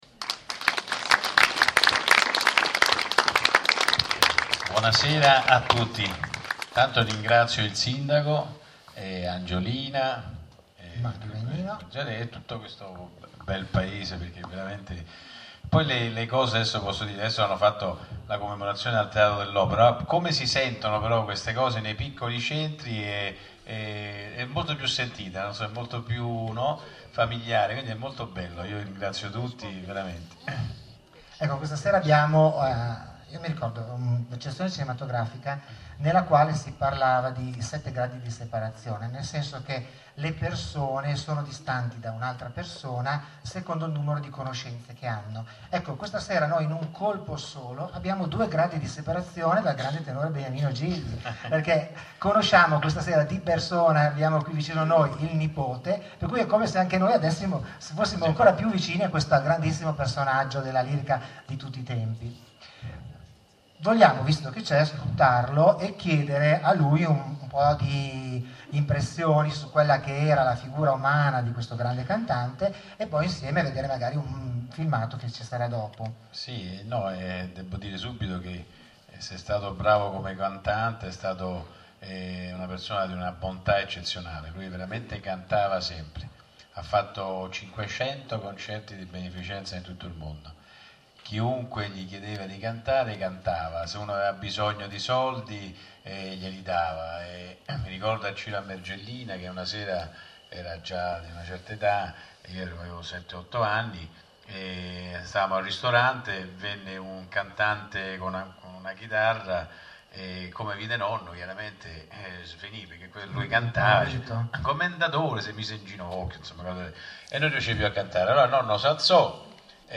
Venerdì 3 Agosto, ore 21.15 – VALVERDE - Piazza della Resistenza
intervista-1.mp3